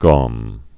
(gôm)